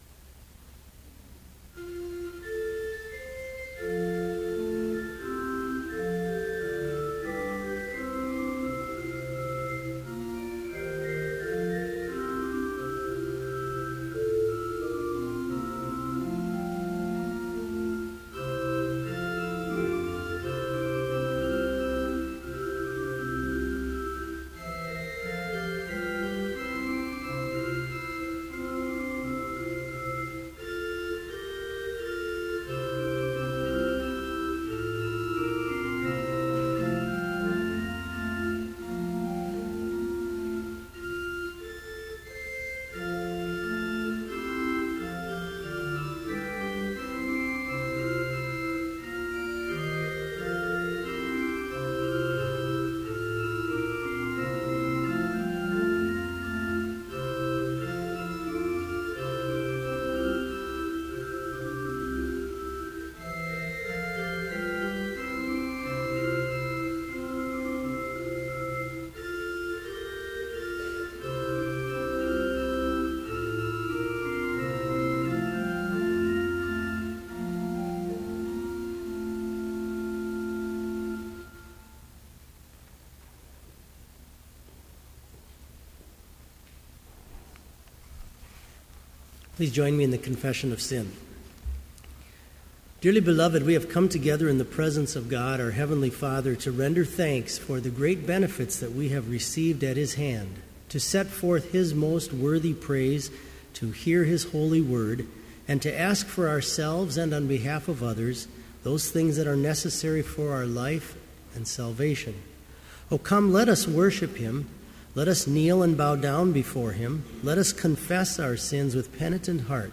Complete service audio for Evening Vespers - February 26, 2014
Versicles & Gloria Patri (led by the choir)
(All may join with the choir to sing Hymn 69, vv. 1 & 8)
Homily Canticle: We Praise You and Acknowledge You - G. Holst, arr. M. Bender We praise You and acknowledge You, O God, to be the Lord, The Father everlasting, by all the earth adored.